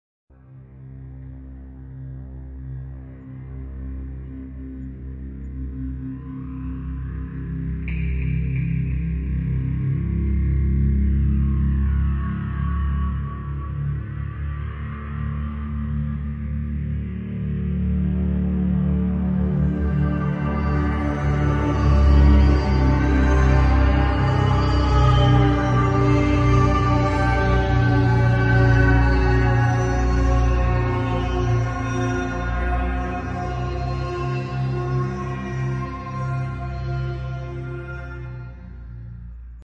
shock4.wav